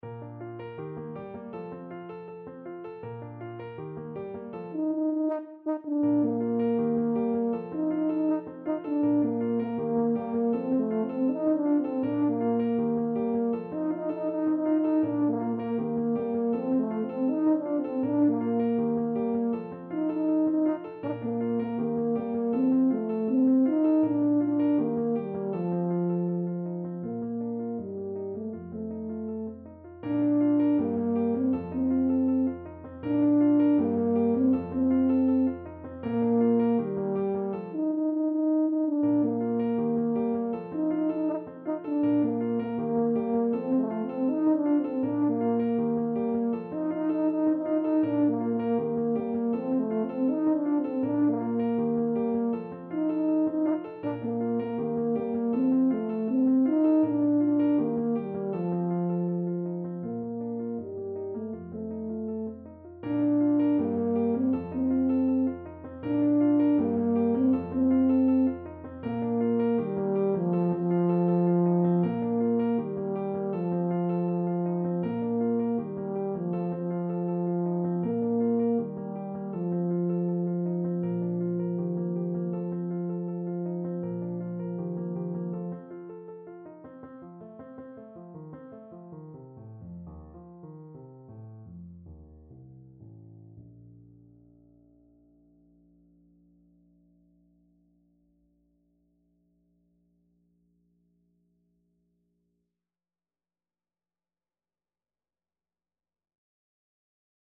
Traditional Trad. La Bamba Tenor Horn version
"La Bamba" is a Mexican folk song, originally from the state of Veracruz, best known from a 1958 adaptation by Ritchie Valens, a top 40 hit in the U.S. charts and one of early rock and roll's best-known songs.
Bb major (Sounding Pitch) (View more Bb major Music for Tenor Horn )
Fast and bright = c. 160